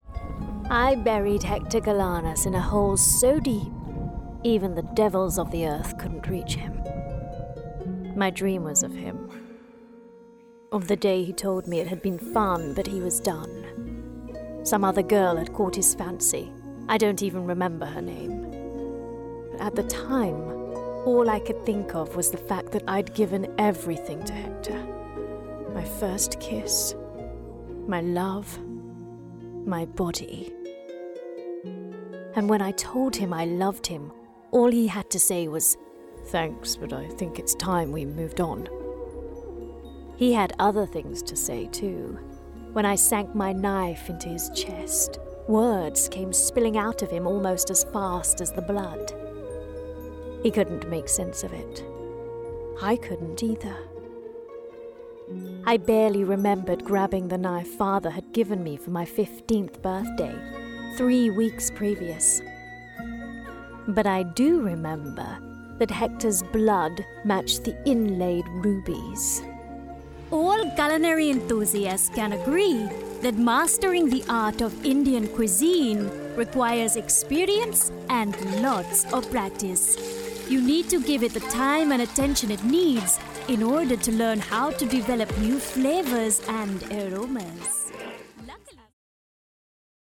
Narration Reel
• Native Accent: London
Fresh, crisp and youthful
Her native London makes for a contemporary and energetic read, though her Asian and West Indian roots provide fantastic flavours to lift any script.
Based in London with a great home studio.